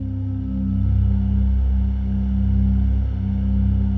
PAD 50-2.wav